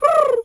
Animal_Impersonations
pigeon_2_call_13.wav